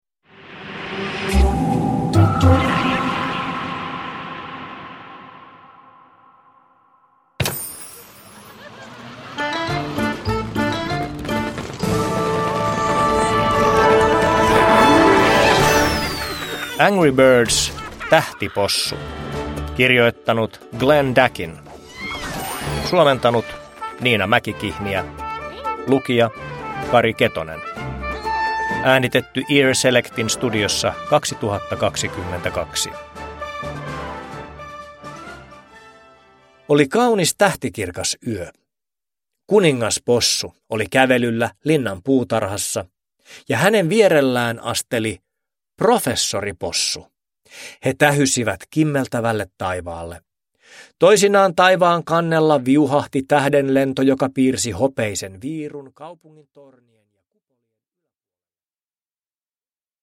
Angry Birds: Tähtipossu (ljudbok) av Glenn Dakin